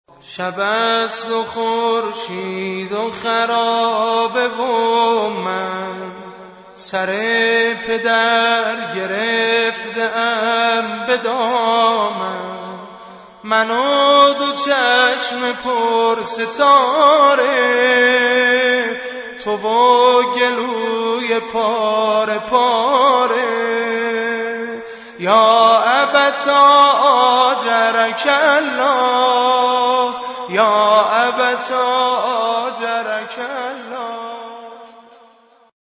نوحه غلامرضا سازگار